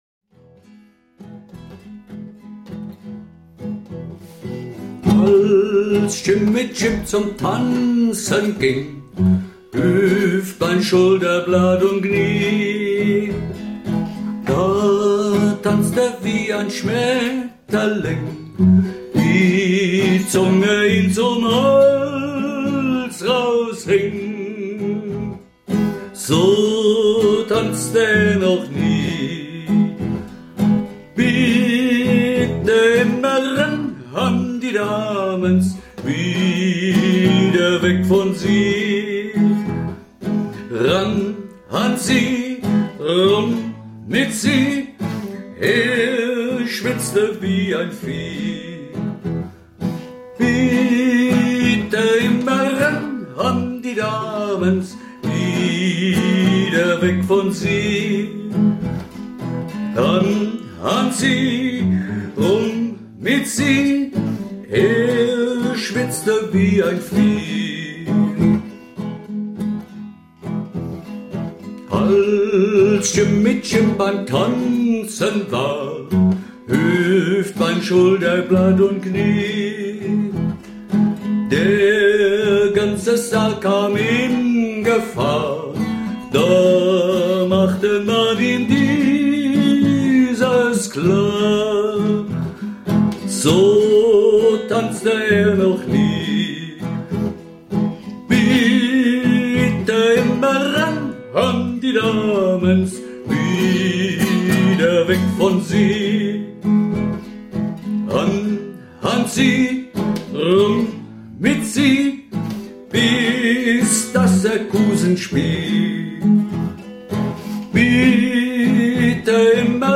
Werkstatt - Aufnahmen 21